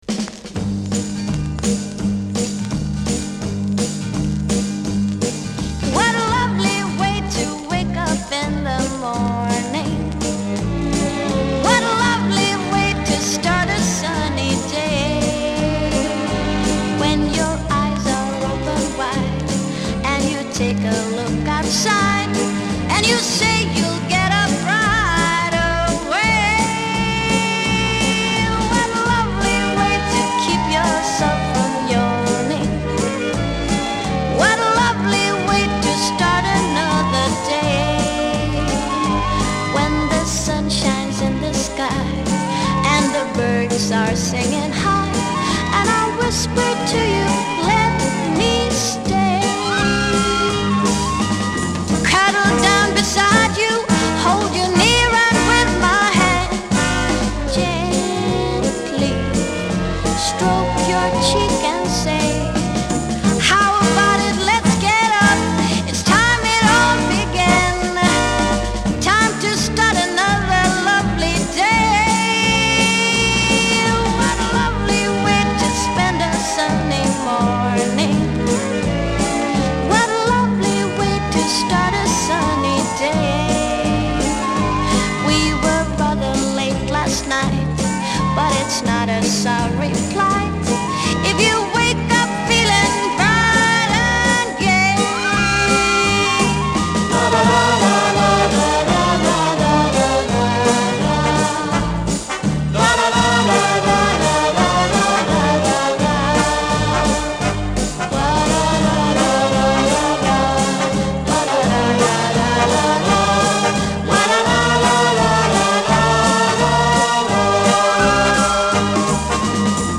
哀愁あふれるメロディーが琴線に触れる